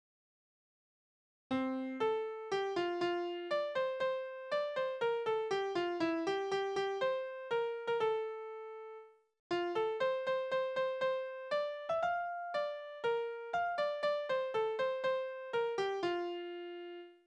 Naturlieder
Tonart: F-Dur
Taktart: C (4/4)
Tonumfang: Undezime
Besetzung: vokal